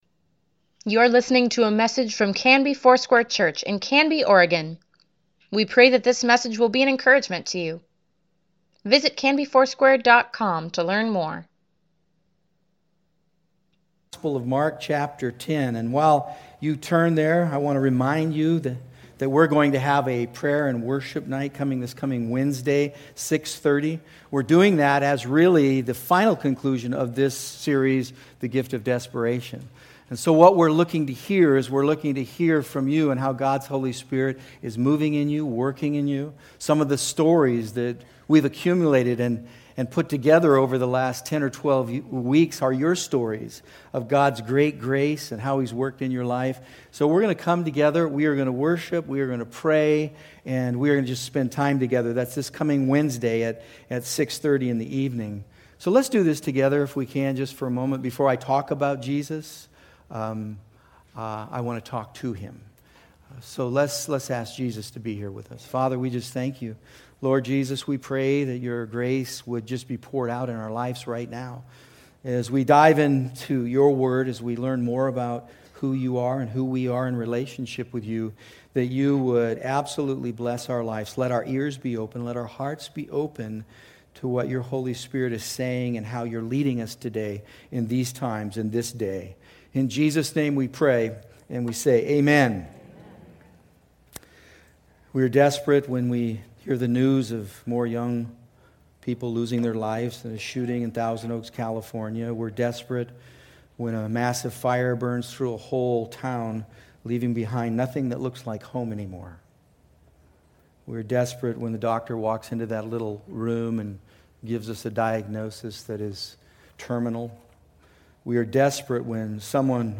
Weekly Email Water Baptism Prayer Events Sermons Give Care for Carus Jesus is Calling You November 11, 2018